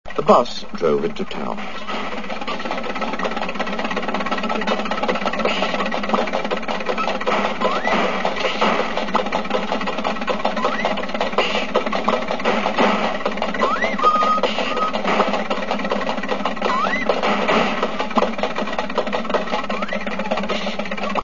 bus2.wav